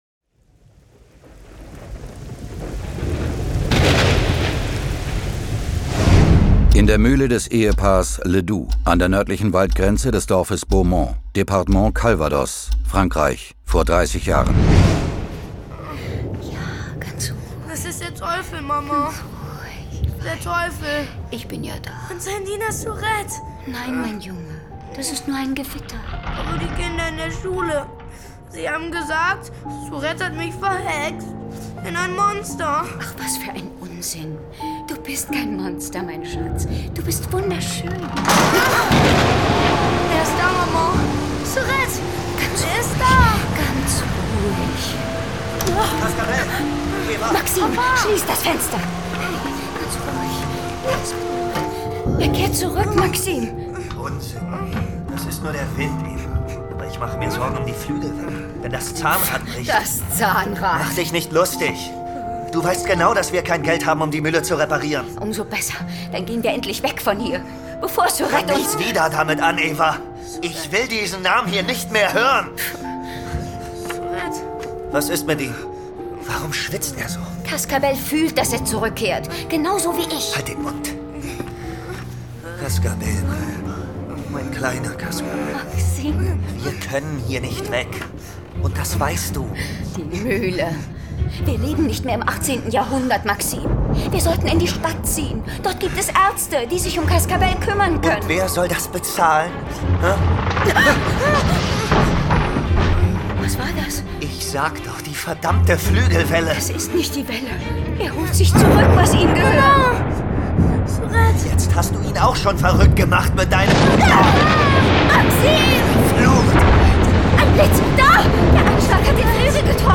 John Sinclair Classics - Folge 16 Der Gnom mit den Krallenhänden. Hörspiel.